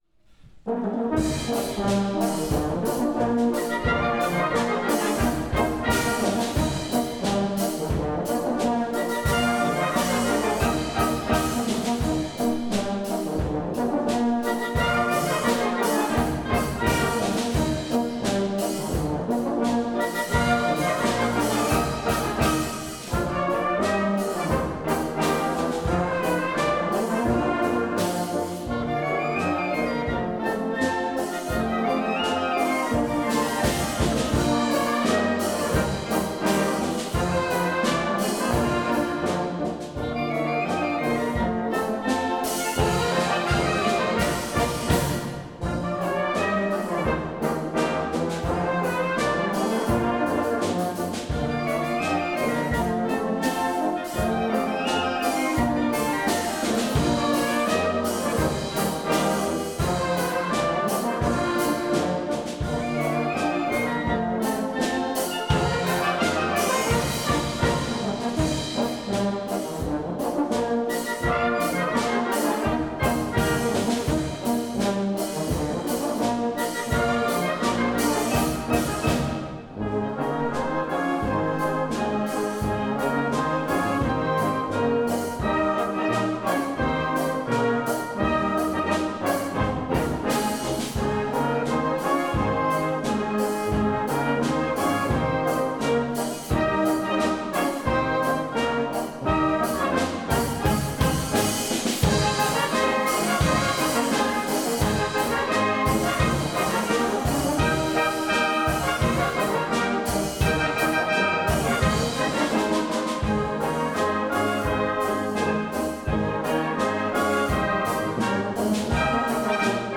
Gattung: Boarischer für Blasorchester
Besetzung: Blasorchester